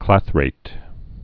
(klăthrāt)